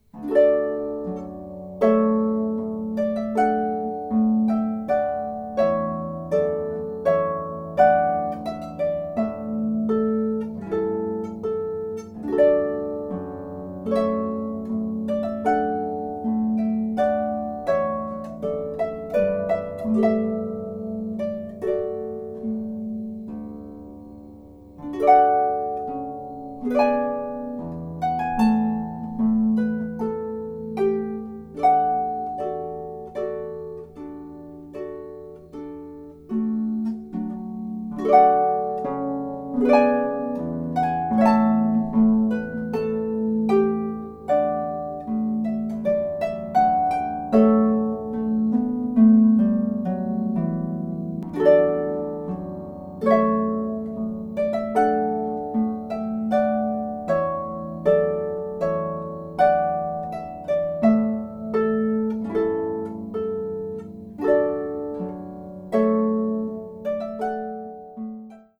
Harpist